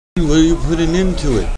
Rex Harrison still tails down from as high as he talks.
Rex Harrison part is simpler than Richard Burton's delivery.